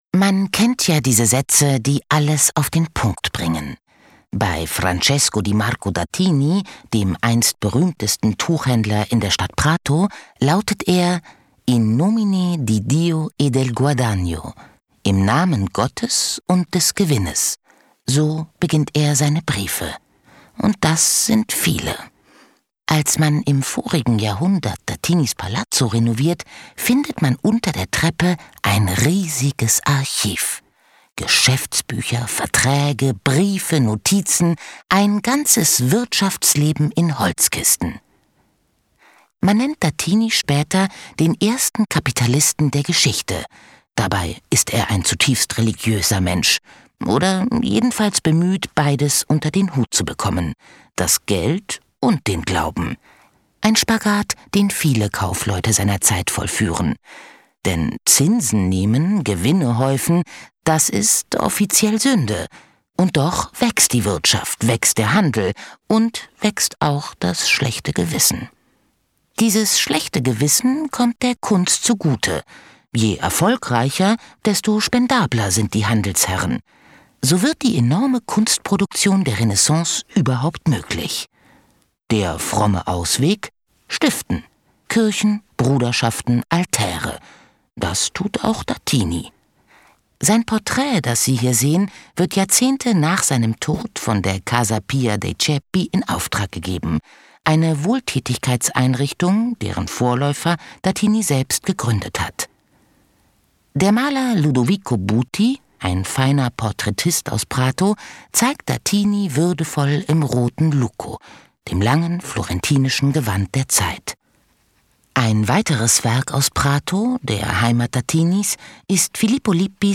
Ausstellung »GÖTTLICH!« im DIMU Freising